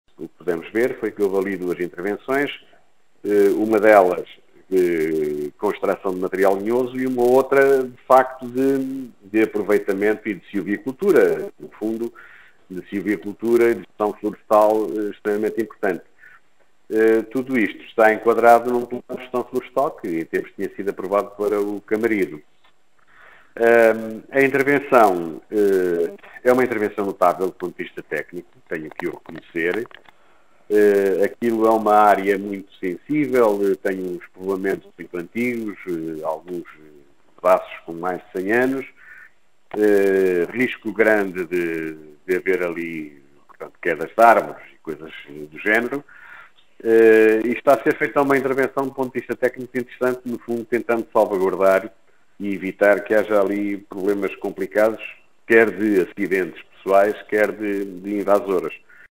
A intervenção na Mata Nacional do Camarido está a ser executada de acordo com o que foi definido no Plano de Gestão Florestal e envolve duas situações, como explica o vereador Guilherme Lagido.